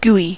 GUI: Wikimedia Commons US English Pronunciations
En-us-GUI.WAV